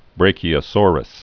(brākē-ə-sôrəs, brăkē-)